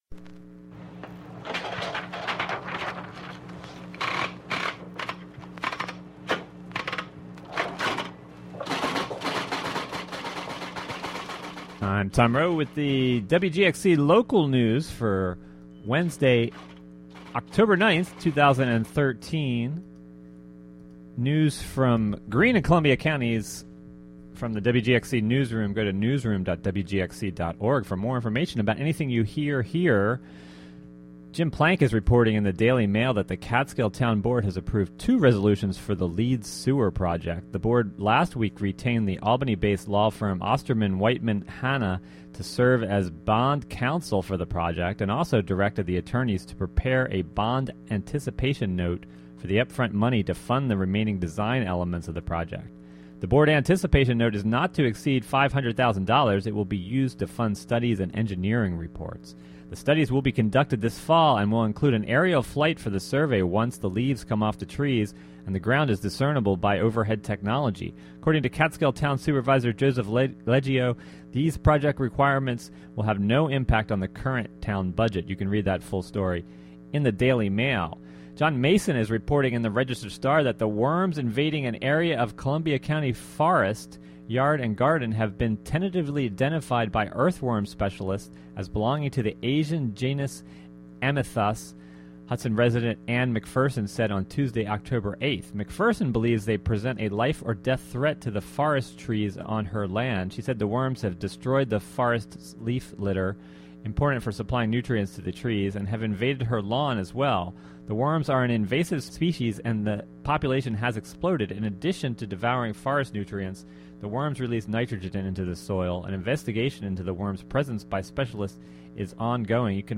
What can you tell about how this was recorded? Live from Wave Farm's Study Center in Acra, NY, To...